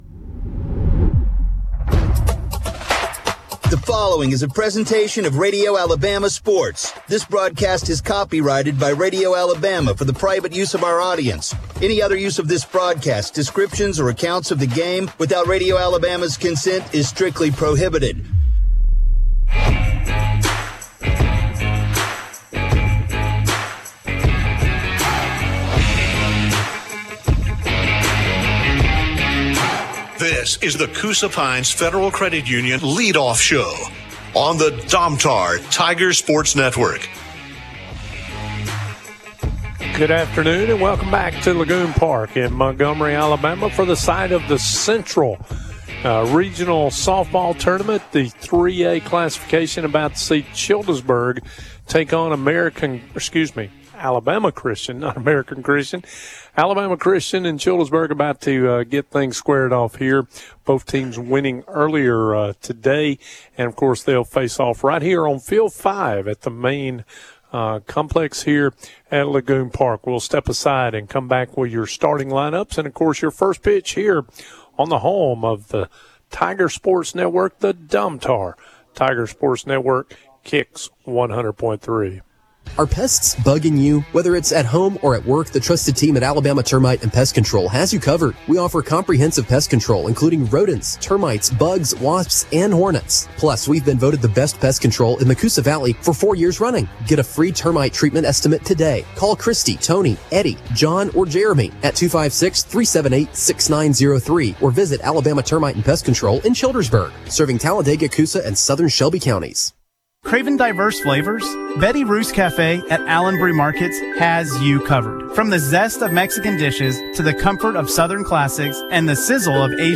call Childersburg's game against Alabama Christian Academy in Game 2 of the Regional Tournament. The Tigers lost 10-0.